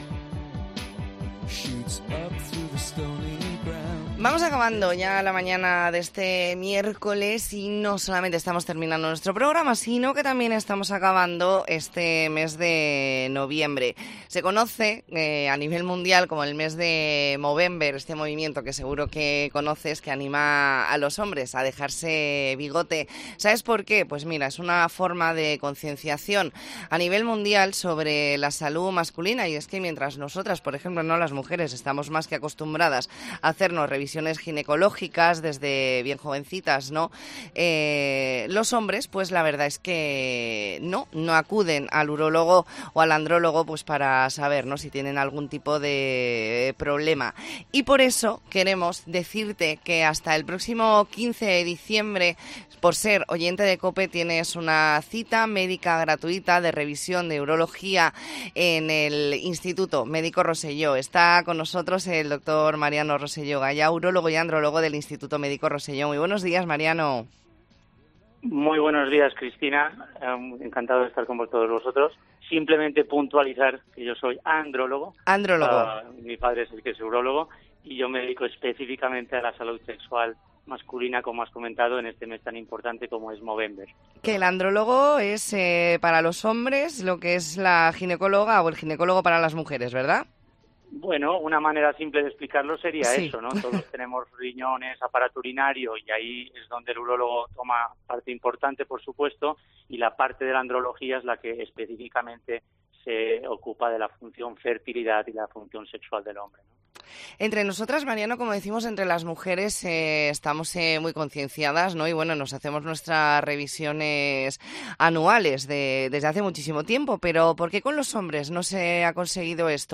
Entrevista en La Mañana en COPE Más Mallorca, miércoles 29 de noviembre de 2023.